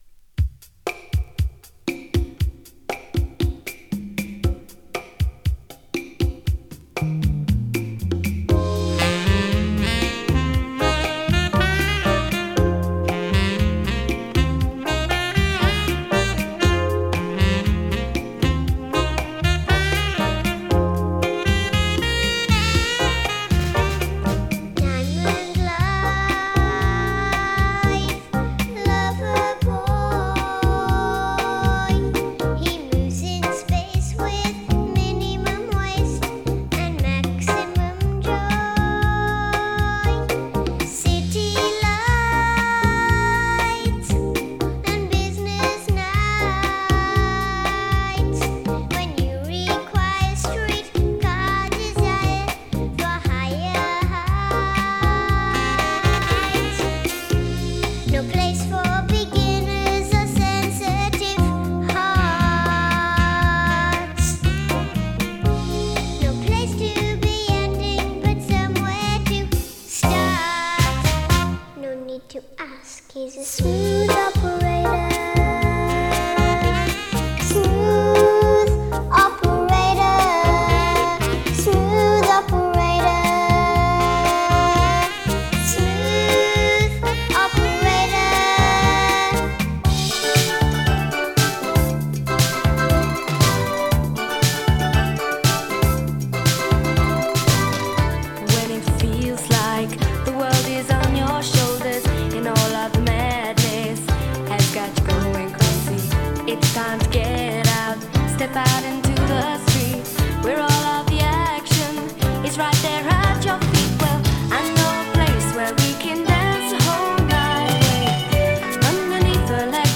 Anyway, we're back to the nice clean and crisp vinyl again!